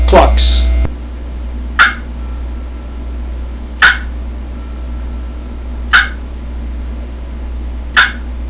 Listen to 9 seconds of clucks
Every stroke on this call produces medium to high pitched hen yelps, cutts and perfect clucks.
• An economically-priced, durable box call made from a combination of superior acoustical woods (walnut, sassafras, cherry) with a great sound.